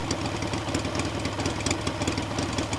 Index of /cstrike/sound/weapons